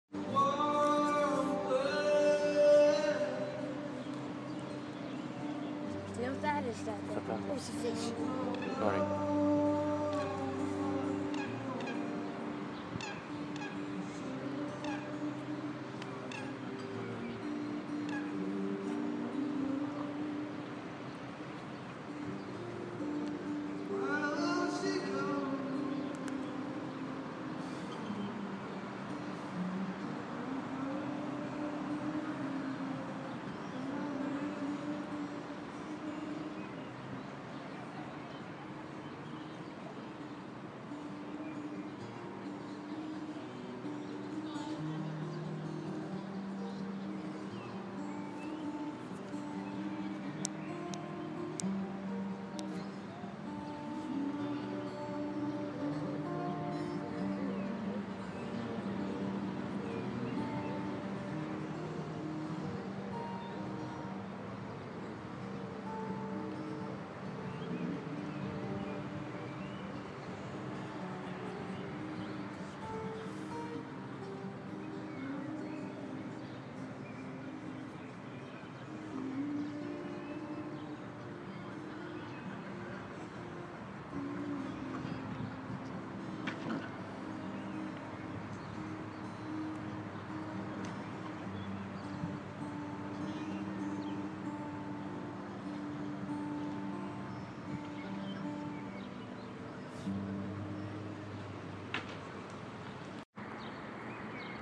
Music by the river